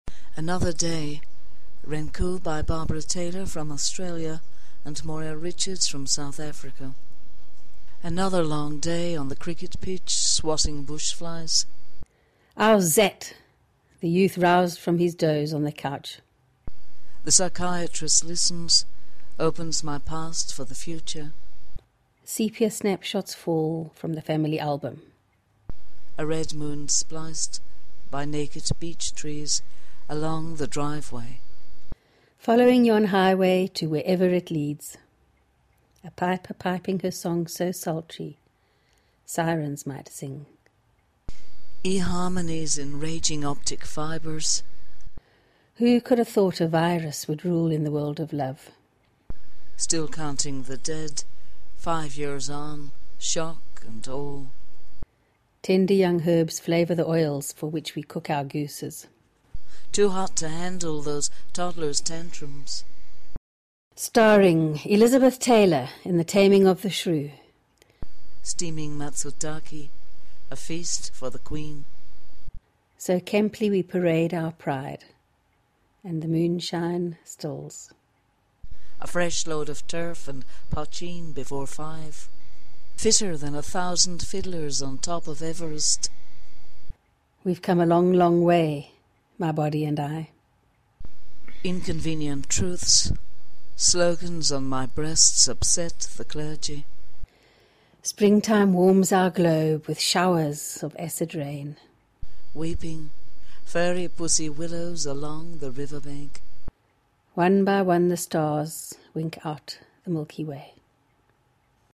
renku sequence